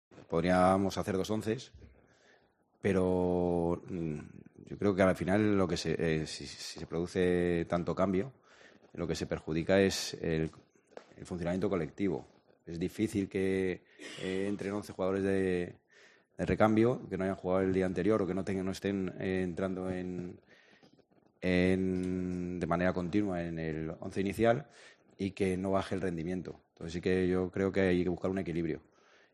“Es un equipo fiel a sí mismo. Les gusta tener el balón. Es un equipo alegre y que tiene muy buen trato de balón y cuando tienen el día son capaces de ganar a cualquiera. A nivel ofensivo en todos los partidos tienen momentos en los que hacen daño”, dijo en la rueda de prensa previa al choque.